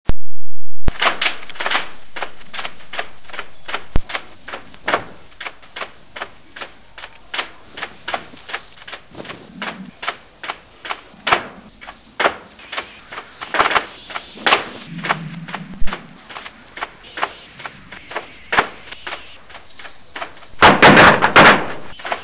Je grösser die Magnitude, desto lauter und tiefer das „Poltern“ der Beben.
Hier hören Sie die Vorbeben-Sequenz des Erdbebens in Landers (Kalifornien) von 1992.
Vorbeben.wma